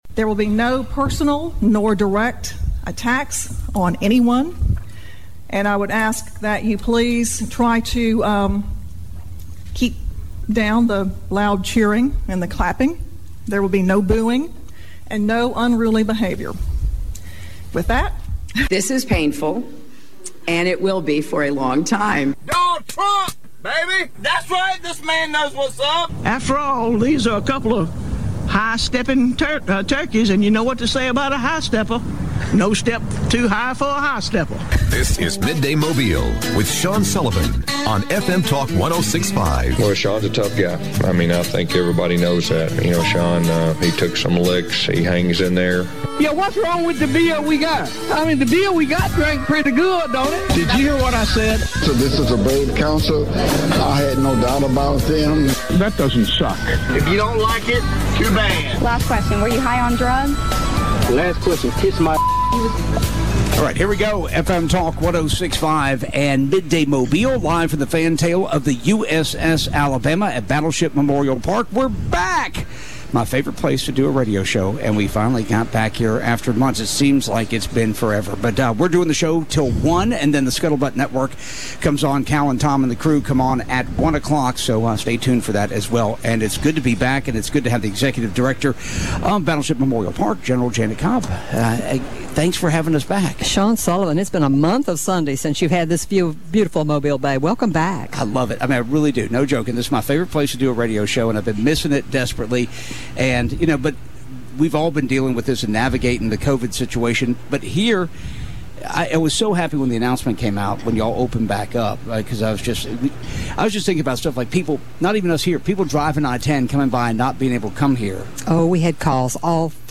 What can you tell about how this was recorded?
Live from the fantail of the USS Alabama